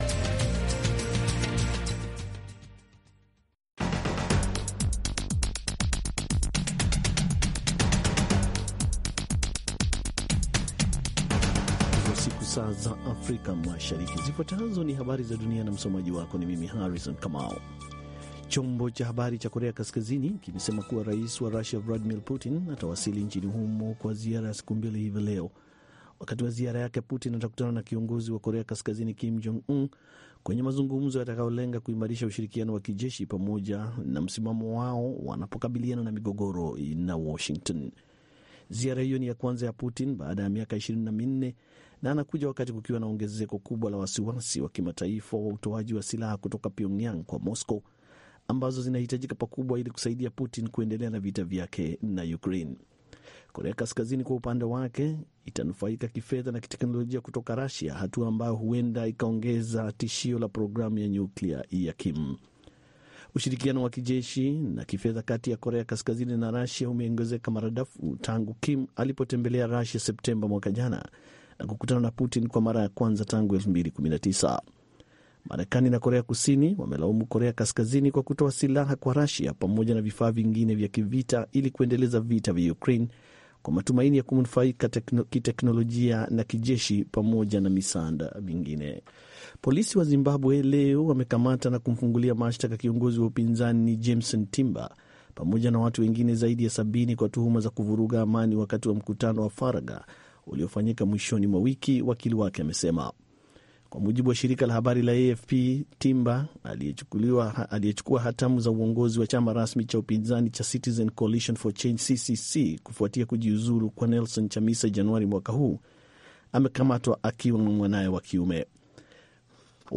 Kwa Undani ni matangazo ya dakika 25 yanayochambua habari kwa undani zaidi na kumpa msikilizaji maelezo ya kina kuliko ilivyo kawaida kuhusu tukio au swala lililojitokeza katika habari.